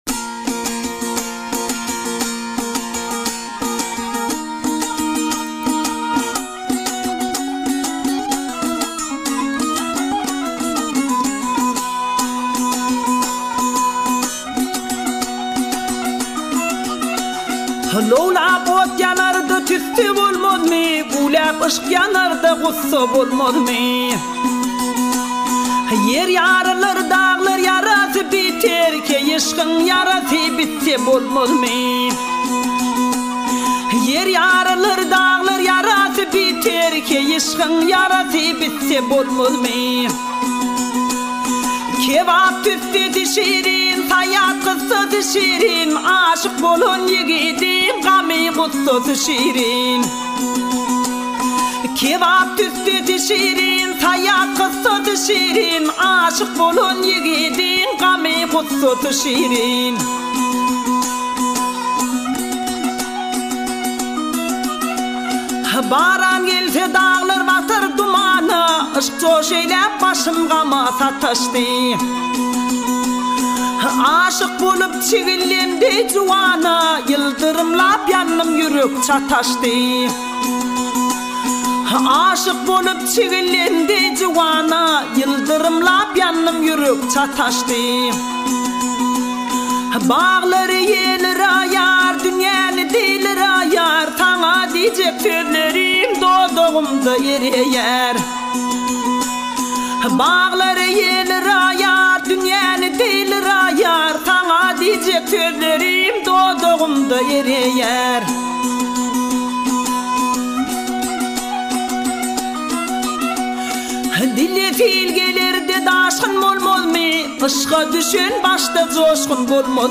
پنج آهنگ ترکمنی بسیار زیبا